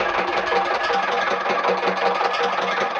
RI_DelayStack_80-05.wav